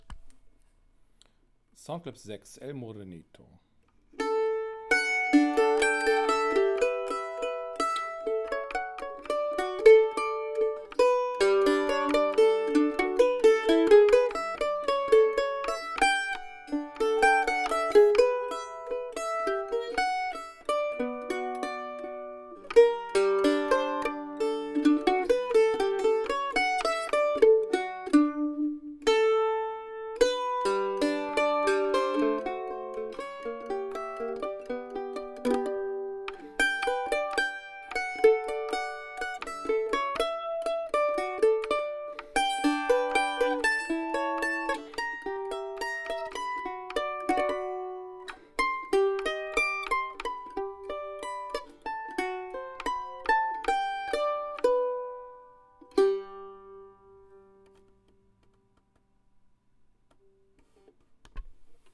Gibson Lloyd Loar F5 Mandolin - 1924 - Sound Clips - New Acoustic Gallery - Finest Instruments